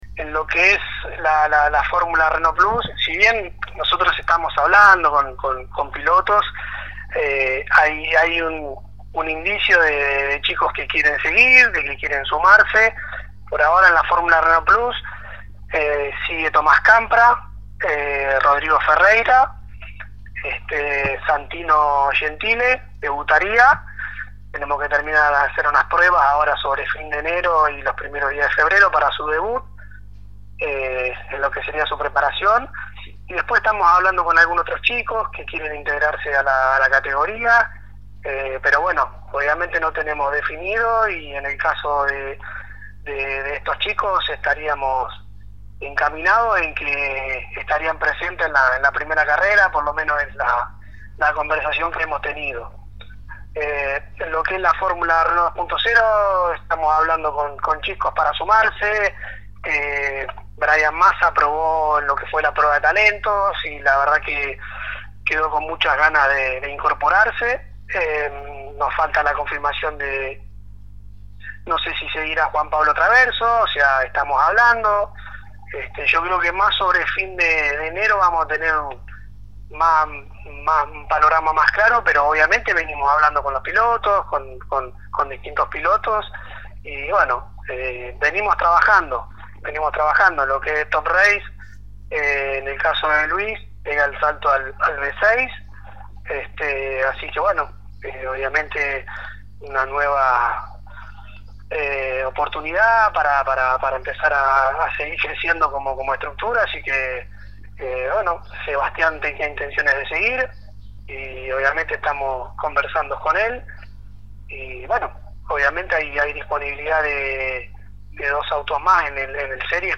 pasó por los micrófonos de Poleman Radio y expresó las expectativas para esta nueva temporada